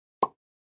Звуки курицы, Minecraft
В подборке есть клоктанье, испуганные крики и другие характерные звуки этого моба. Отличное качество аудио без посторонних шумов.